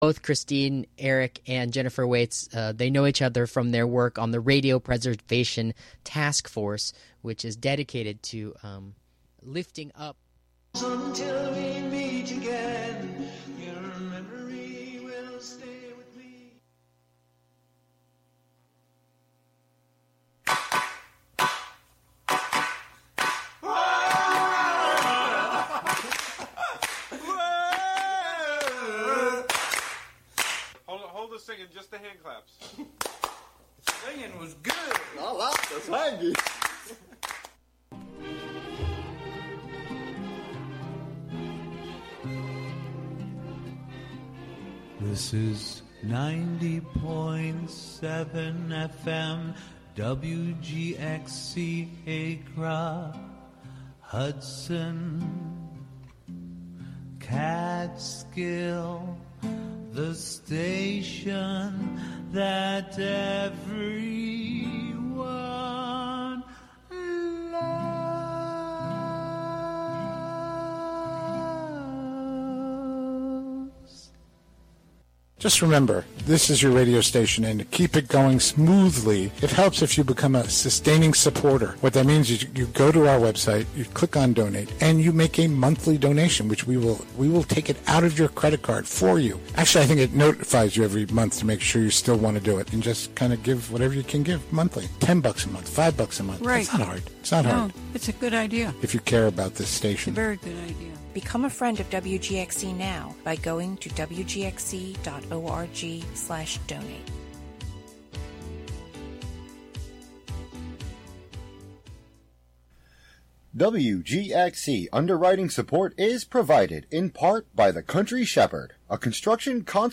"Long Pause" is an invitation to slow down into interstitial realms through sound. With a blend of song, field recording, archival audio, and conversation, the show explores the thin spaces between the ordinary and sacred, human and nonhuman, particular and universal, and visible and invisible, through a different sonic theme and/or medium each month.